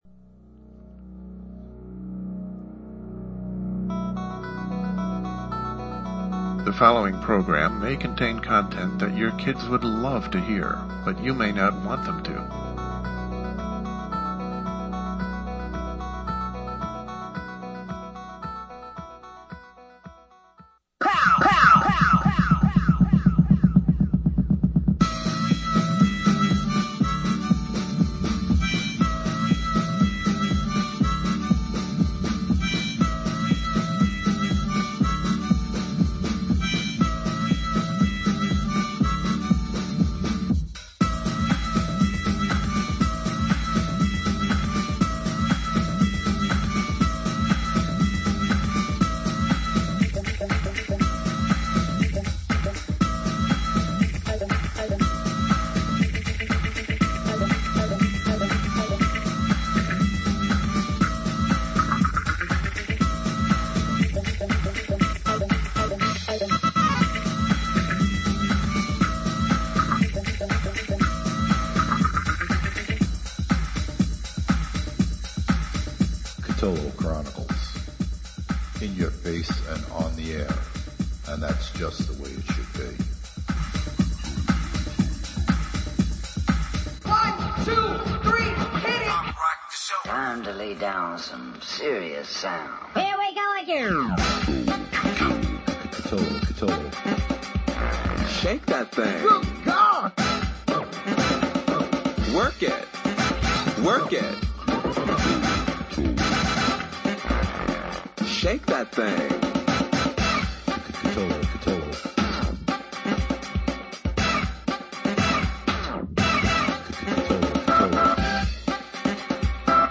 If you can digest your Thanksgiving dinner you can digest the Nov. 24 edition of this show, LIVE at 9:00 p.m. Eastern time.